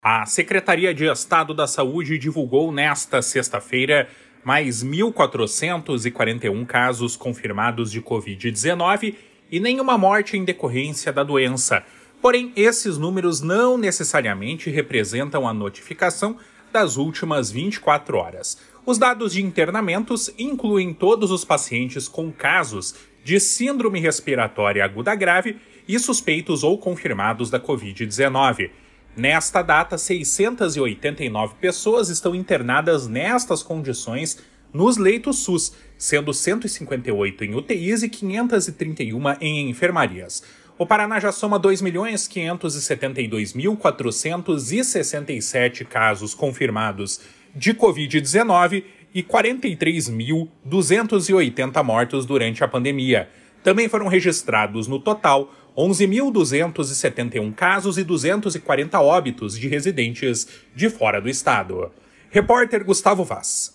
Boletim Covid 17-06.mp3